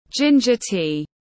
Trà gừng tiếng anh gọi là ginger tea, phiên âm tiếng anh đọc là /ˈdʒɪn.dʒər tiː/
Ginger tea /ˈdʒɪn.dʒər tiː/